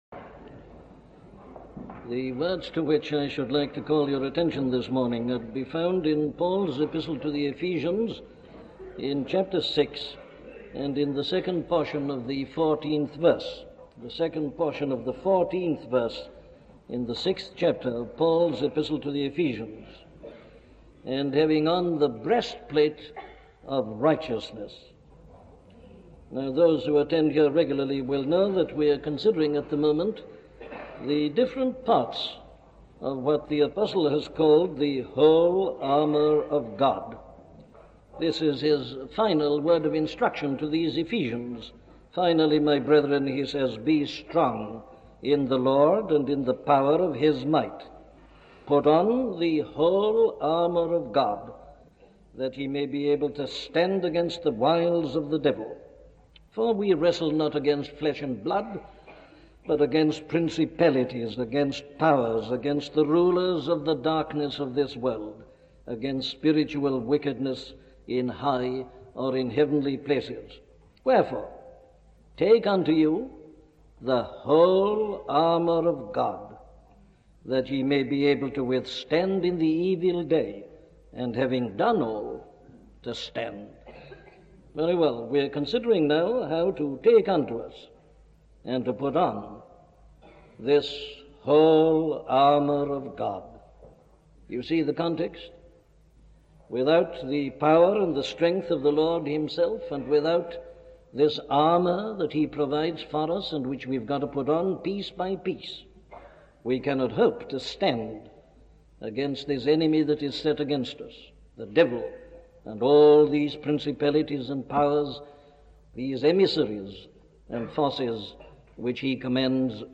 The Breastplate of Righteousness - a sermon from Dr. Martyn Lloyd Jones
Listen to the sermon on Ephesians 6:14 'The Breastplate of Righteousness' by Dr. Martyn Lloyd-Jones